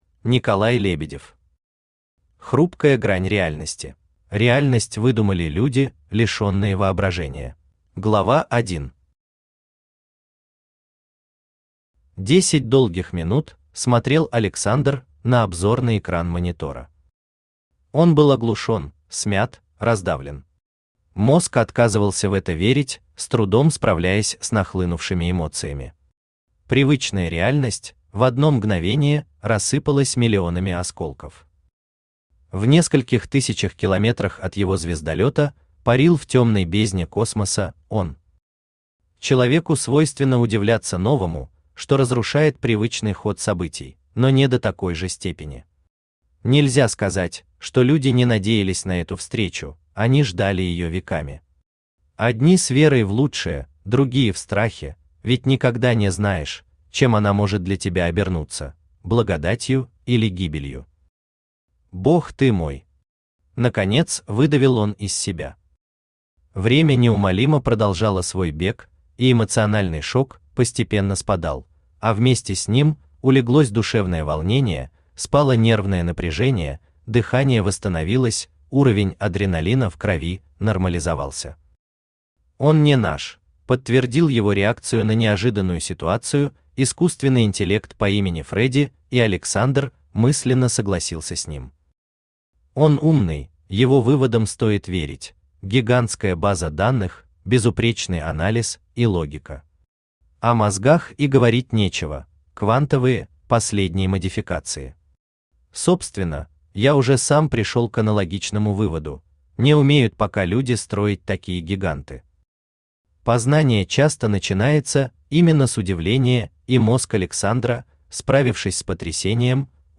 Аудиокнига Хрупкая грань реальности | Библиотека аудиокниг
Aудиокнига Хрупкая грань реальности Автор Николай Лебедев Читает аудиокнигу Авточтец ЛитРес.